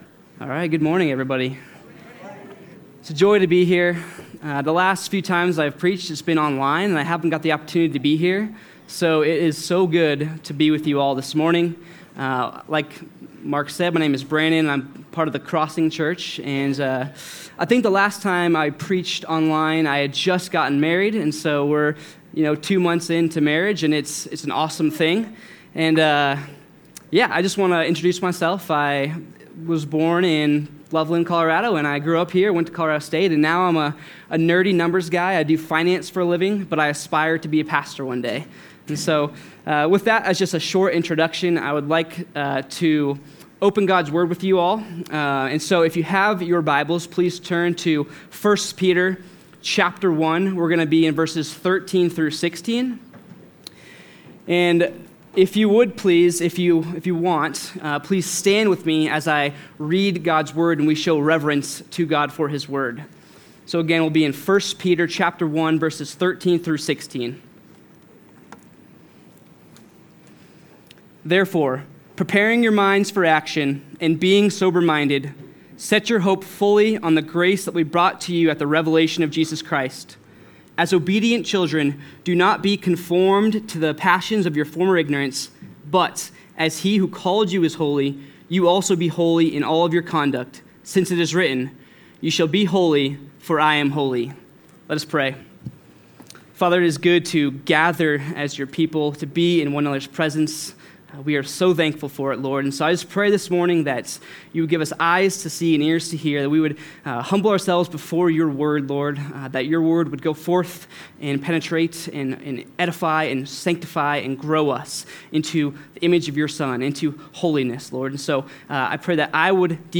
Location: High Plains Harvest Church Passage: 1 Peter 2:11-17